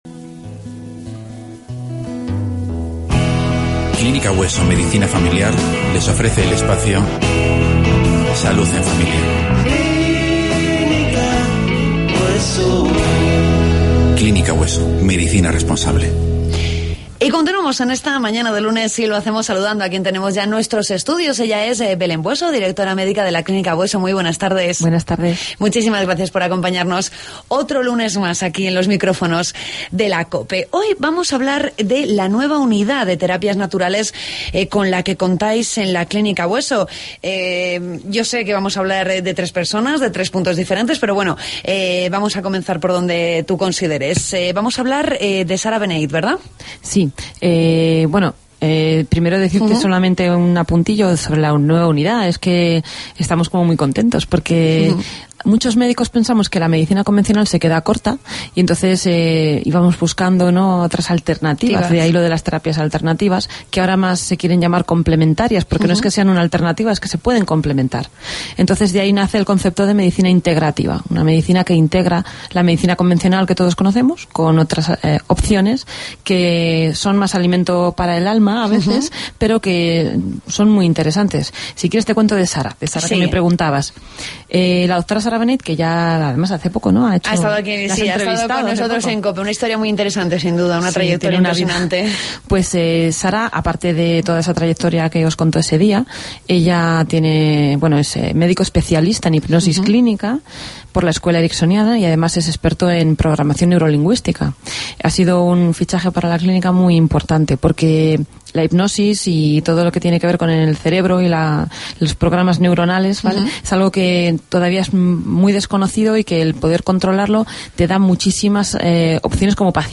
Entrevistamos a la consejera de Agricultura, María Luisa Soriano.